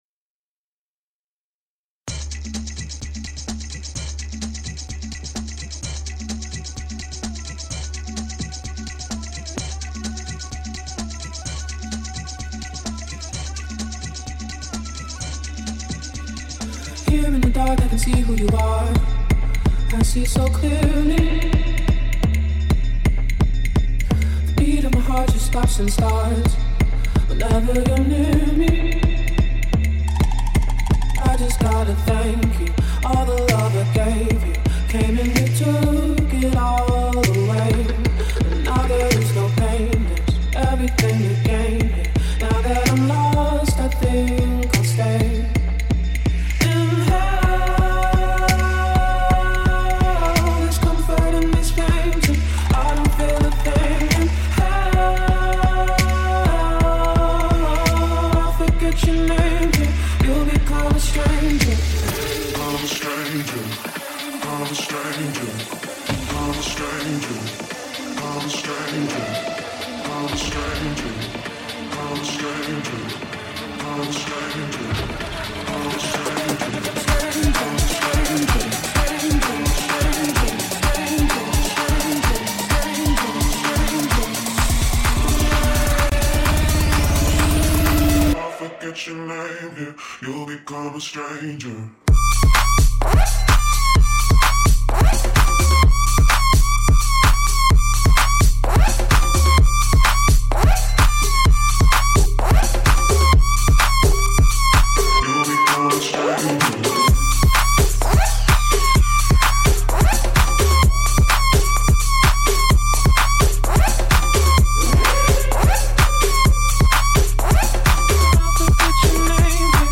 Категория: Электро музыка » Дабстеп